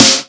edm-snare-03.wav